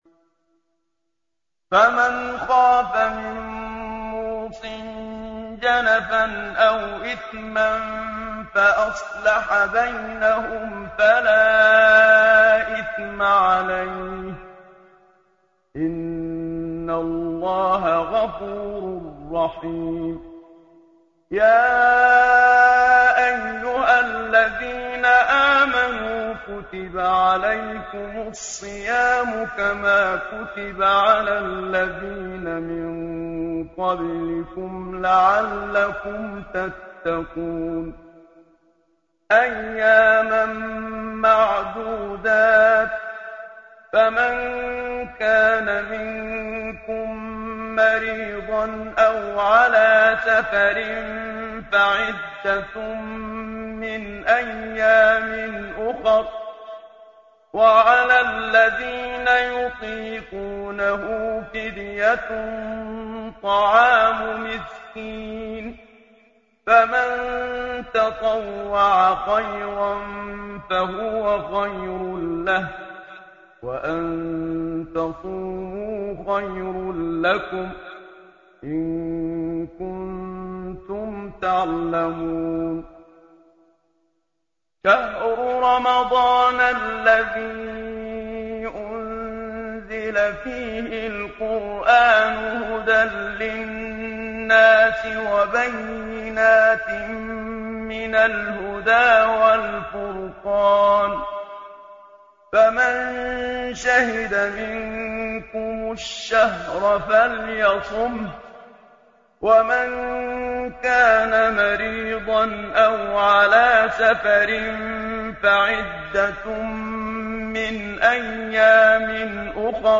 تلاوت روزانه قرآن کریم - مؤسسه راهیان کوثر غدیر یزد